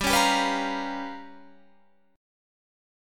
G7#9b5 chord